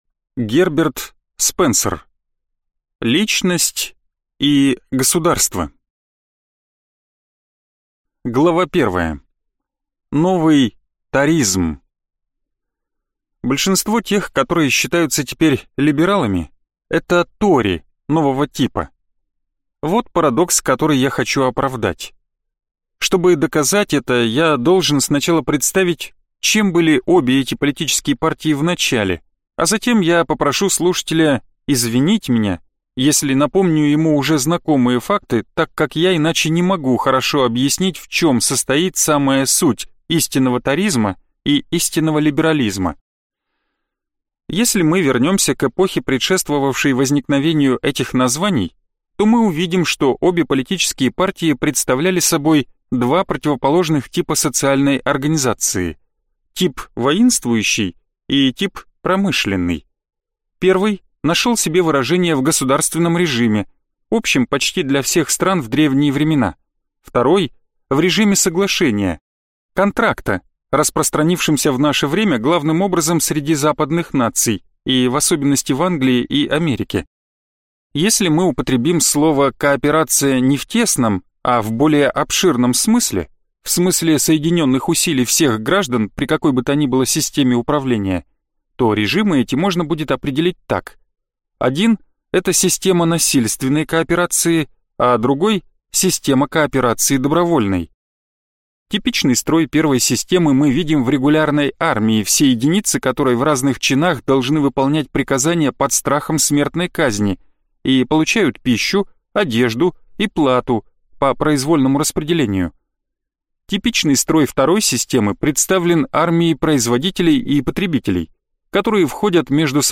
Аудиокнига Личность и государство | Библиотека аудиокниг
Прослушать и бесплатно скачать фрагмент аудиокниги